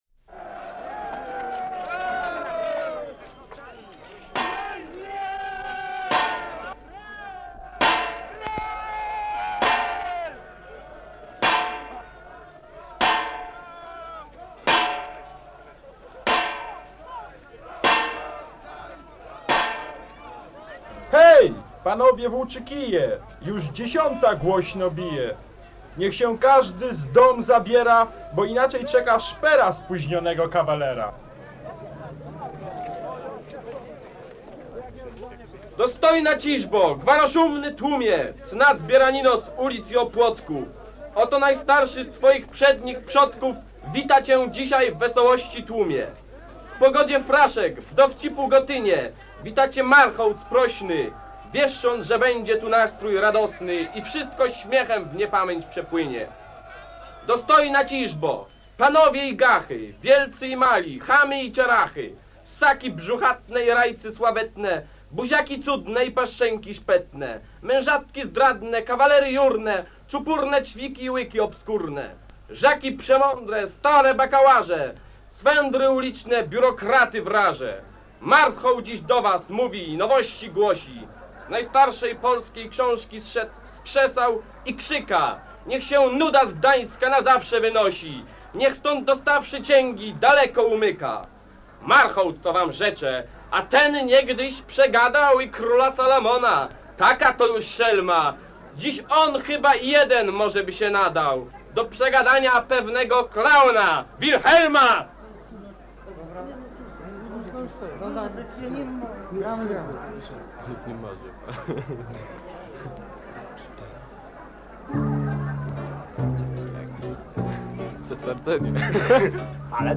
Audycja sylwestrowo-noworoczna z fragmentami programu Teatrzyku Tralabomba